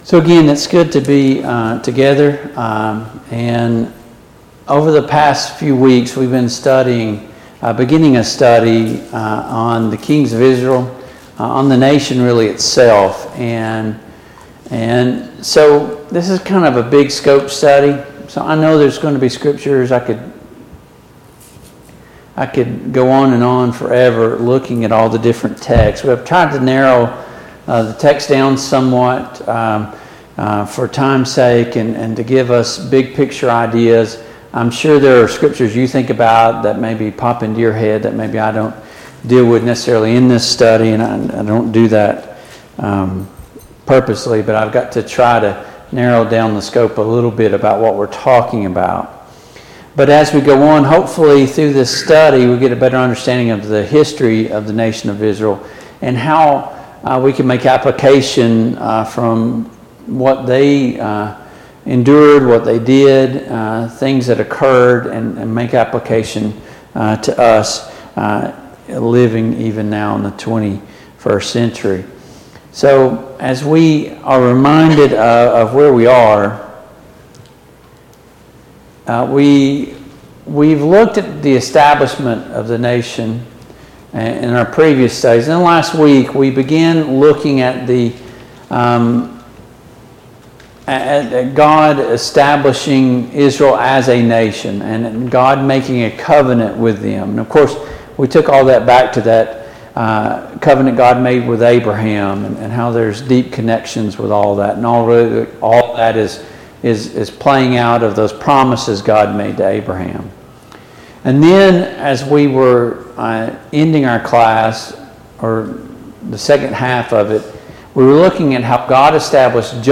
The Kings of Israel Passage: I Samuel 8-9 Service Type: Mid-Week Bible Study Download Files Notes « The End 2.